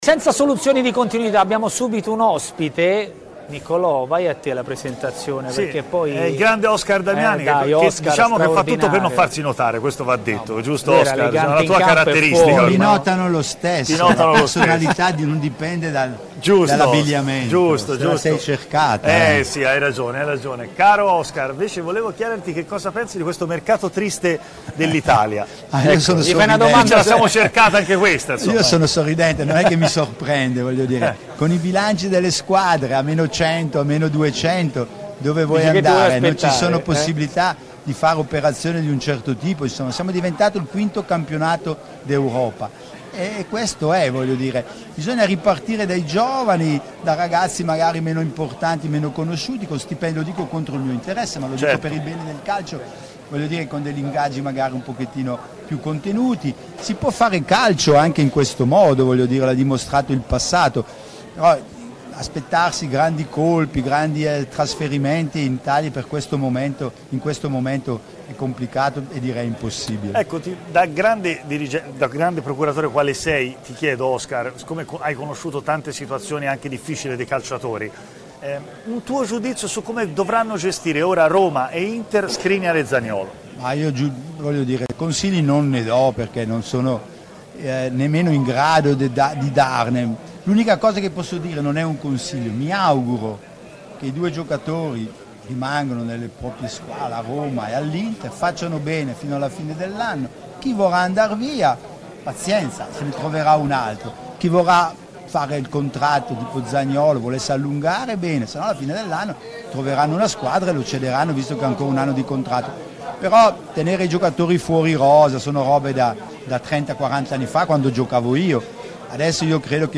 in diretta dallo Sheraton di Milano
Speciale Calciomercato